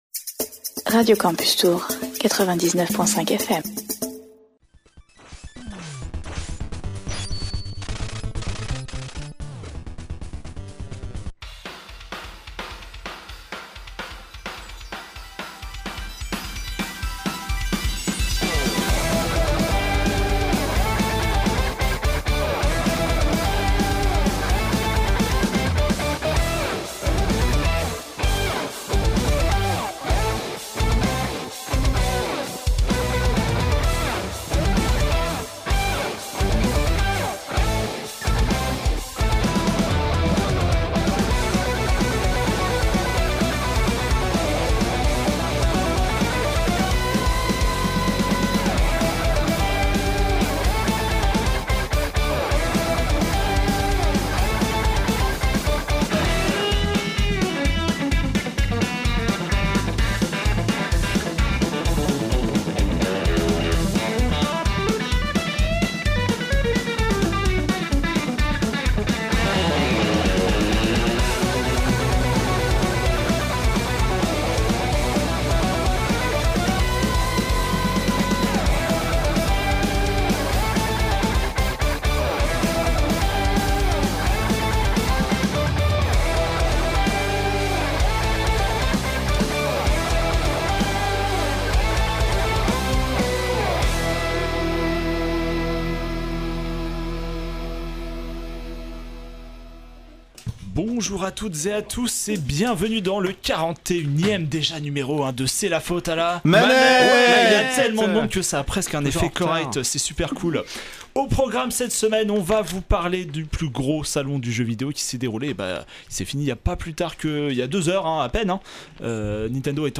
Une intro dynamique